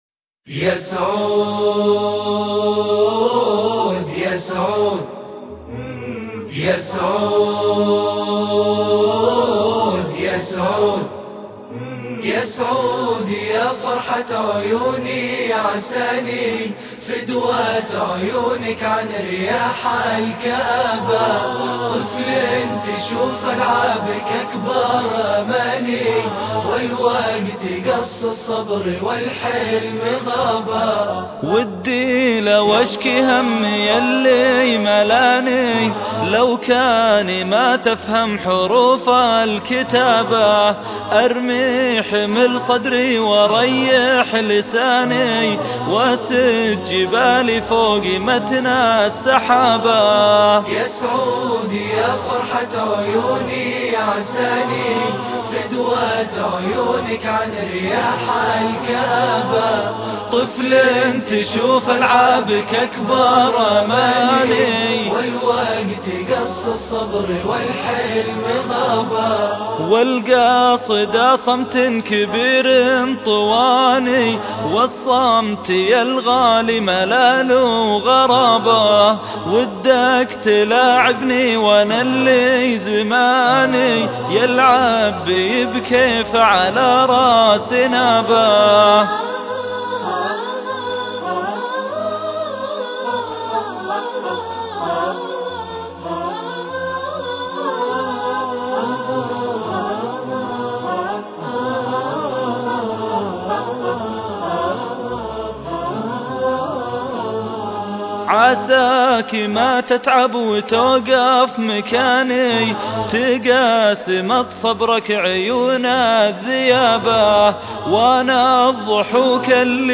شيلات